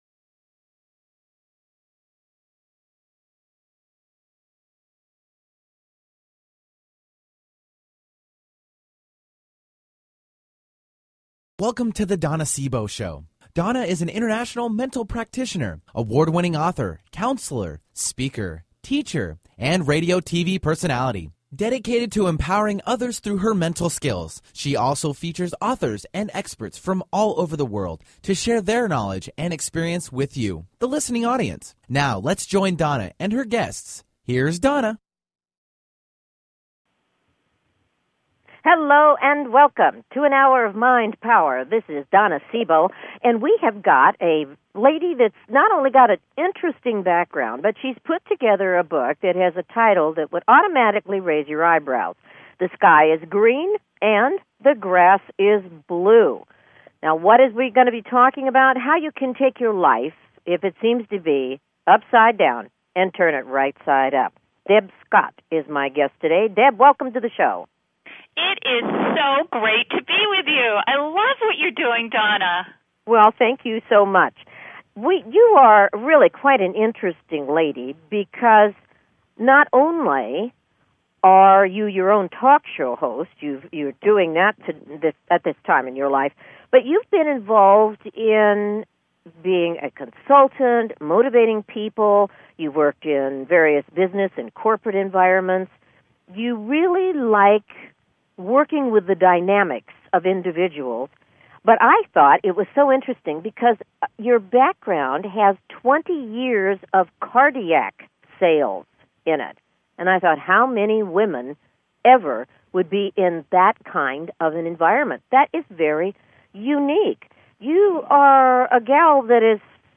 Talk Show Episode, Audio Podcast
Her interviews embody a golden voice that shines with passion, purpose, sincerity and humor.